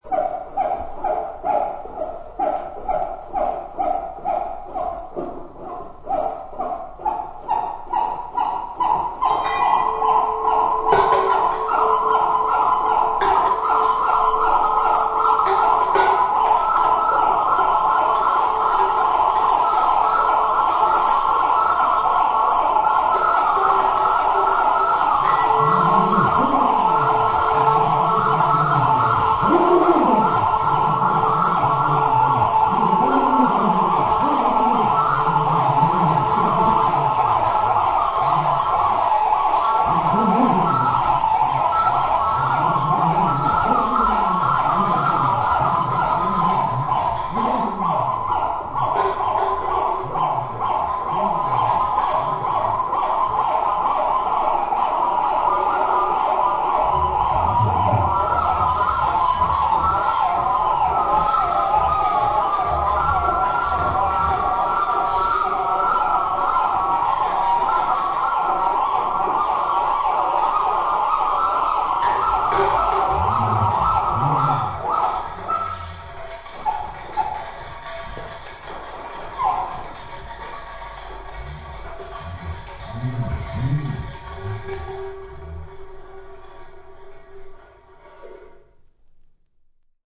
No Audience Side